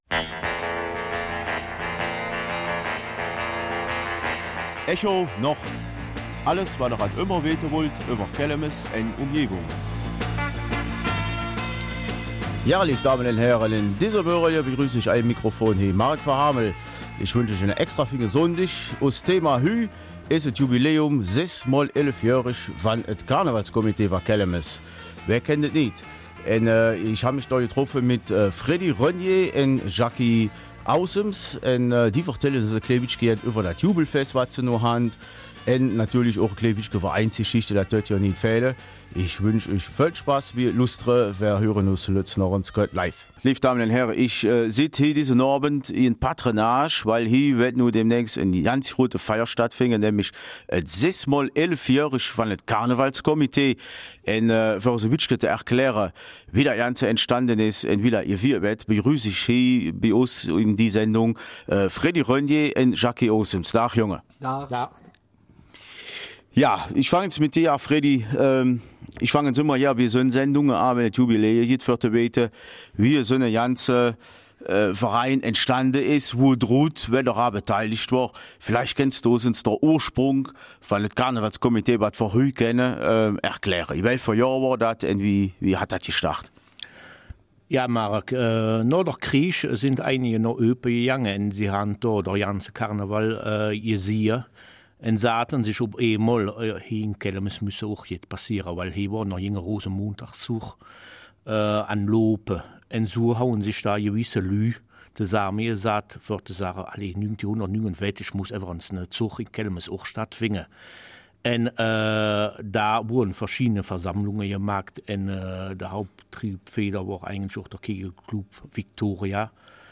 Kelmiser Mundart: 6 x 11 Jahre Karnevalskomitee Kelmis